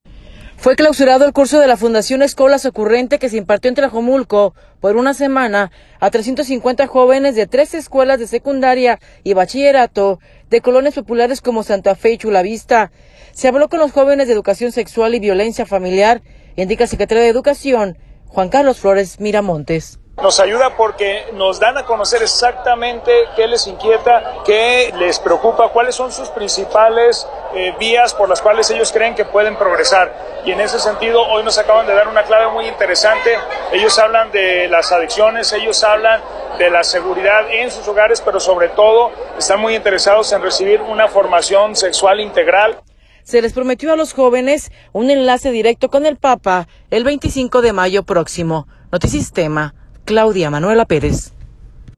Fue clausurado el curso de la Fundación Scholas Occurrentes que se impartió en Tlajomulco por una semana a 350 jóvenes de 13 escuelas de secundaria y bachillerato de colonias populares, como Santa Fe y Chulavista. Se habló con los jóvenes de educación sexual y violencia familiar, indica el secretario de Educación, Juan Carlos Flores Miramontes.